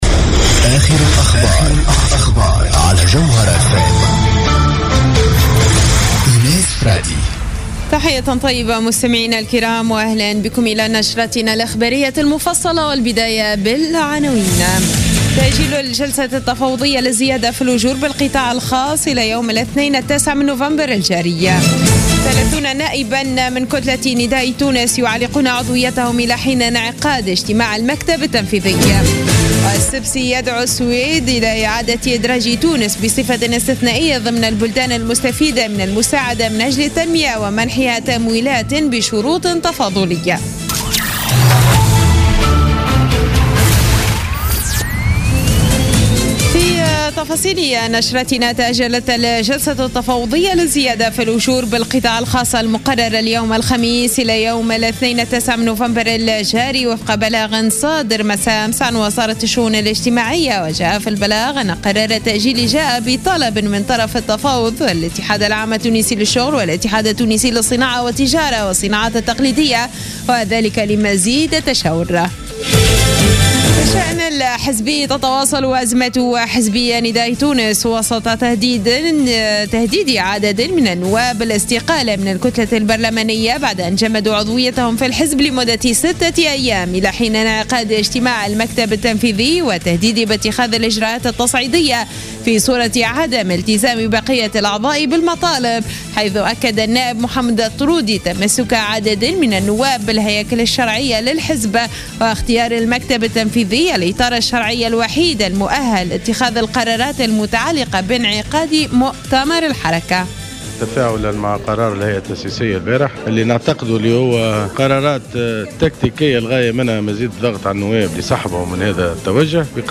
نشرة أخبار منتصف الليل ليوم الخميس 5 نوفمبر 2015